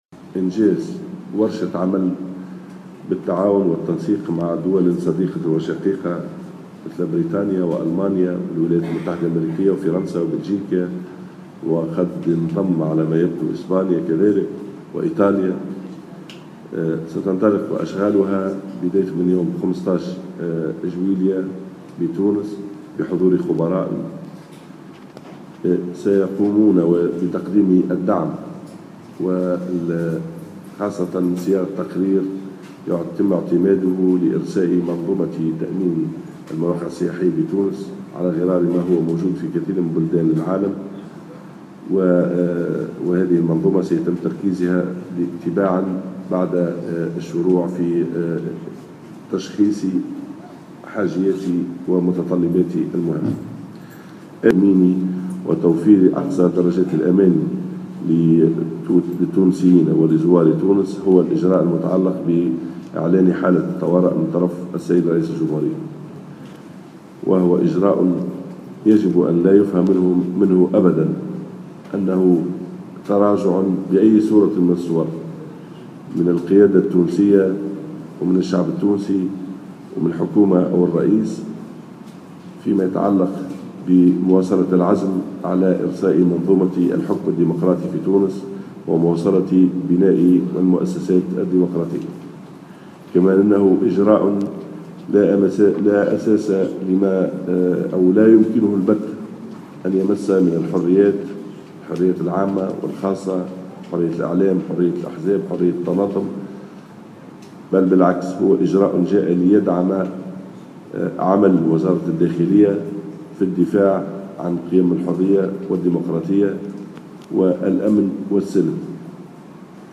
أكد وزير الداخلية محمد ناجم الغرسلي في تصريح